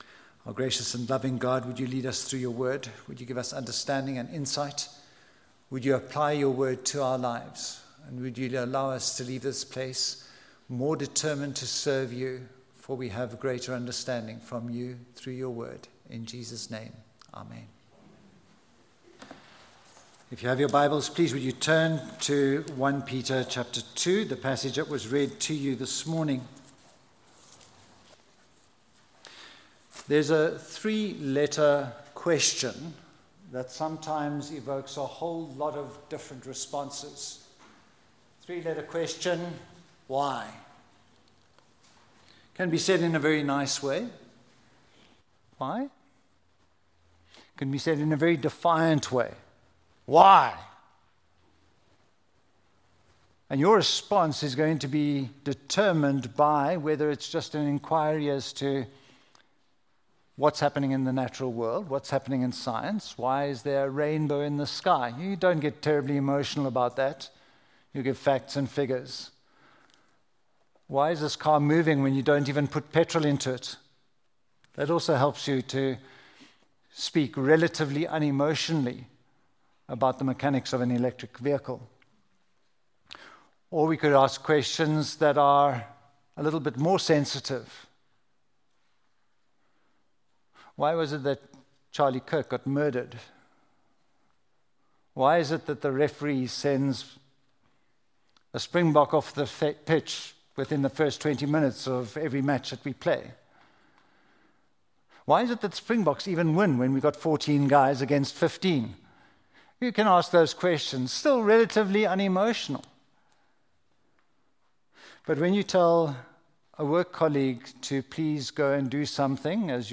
1 Peter 2:11-25 Service Type: Sunday Morning Jesus is our Sacrifice Jesus is our Example Jesus is our Archetype « Thankful!